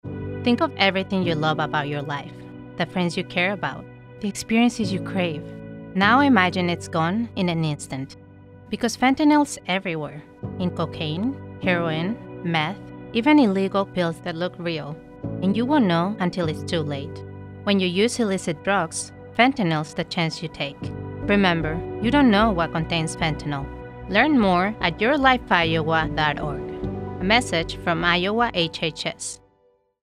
:30 Radio Spot | Fentanyl | 18-30 Female